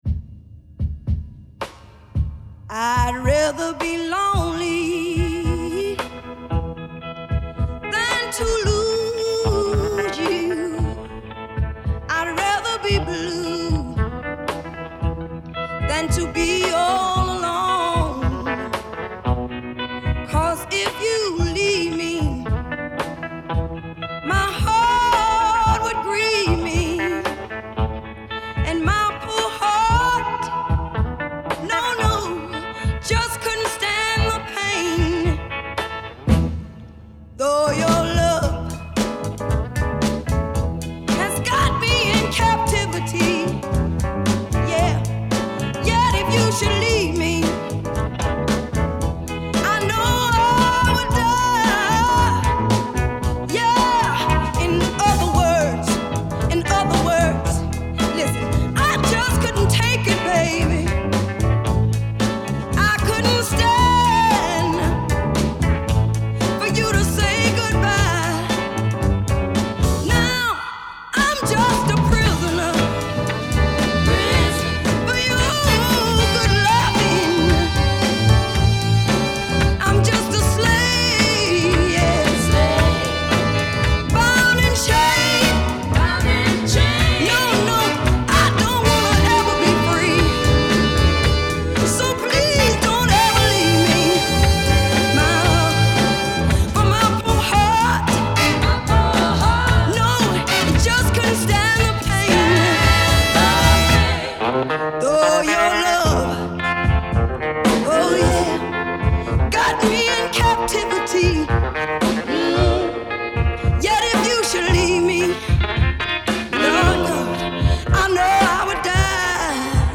Tag: southern soul